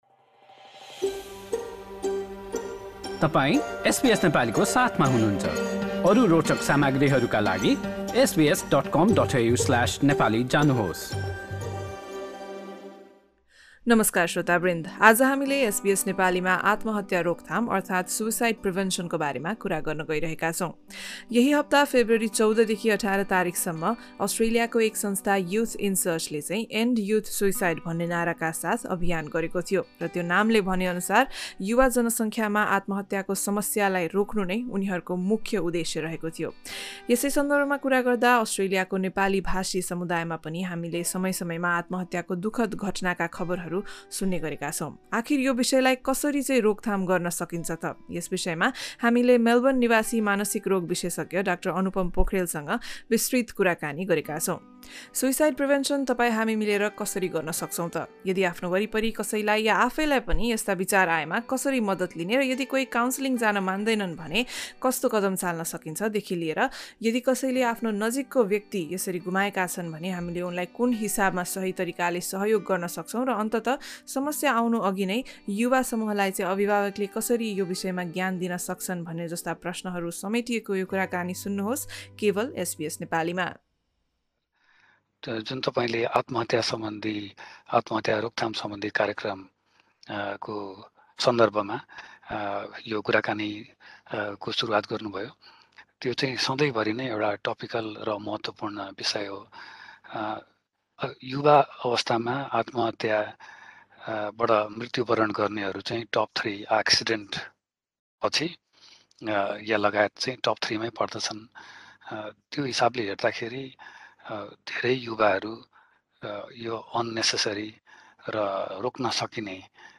कुराकानी। यस कुराकानीमा समावेश विषयहरू: आत्महत्या रोकथाम कसरी गर्न सकिन्छ?